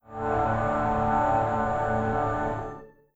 Samsung Galaxy S50 Startup.wav